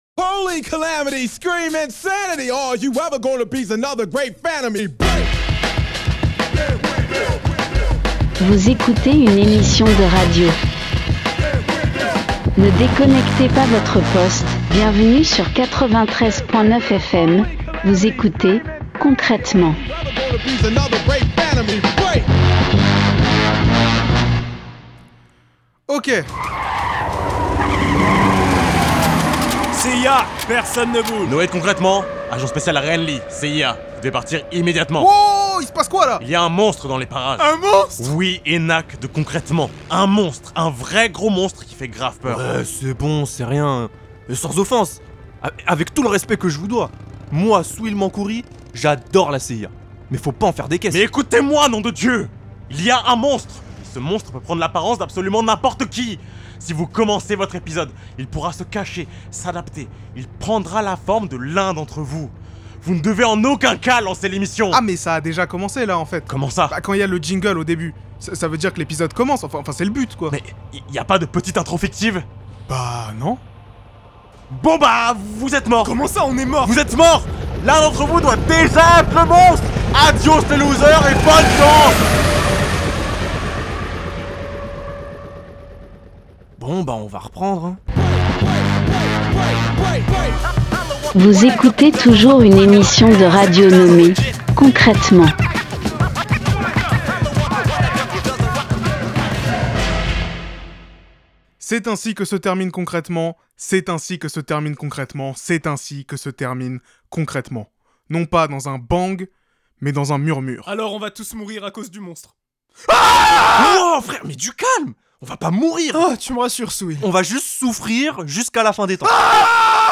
Concrètement : Est-ce que la post-ironie va nous tuer ? (EPISODE FINAL) Partager Type Création sonore Société samedi 20 septembre 2025 Lire Pause Télécharger C'est la fin.